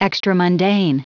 Prononciation du mot extramundane en anglais (fichier audio)
Prononciation du mot : extramundane